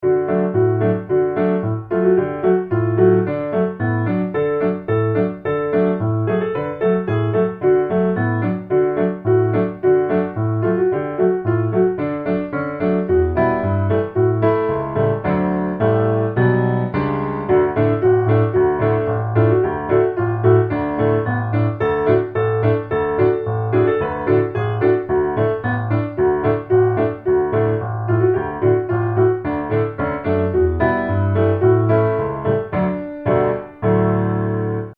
Gospel
Traditional American melody
D Major